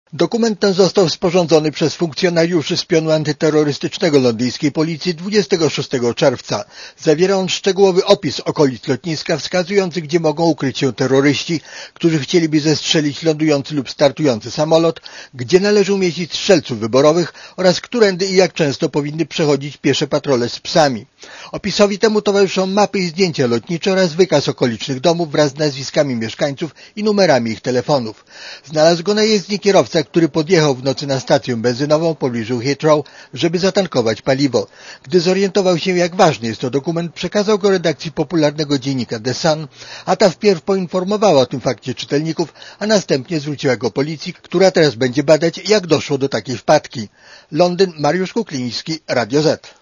Korespondencja z Londynu